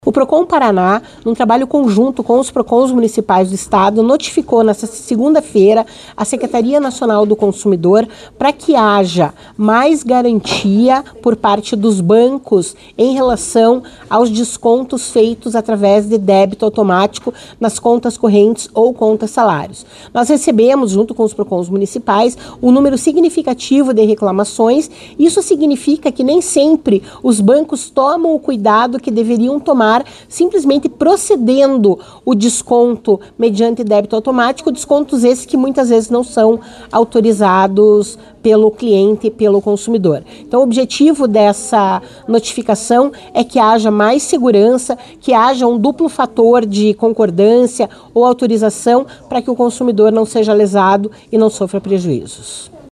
Sonora da coordenadora do Procon-PR, Claudia Silvano, sobre os débitos automáticos não autorizados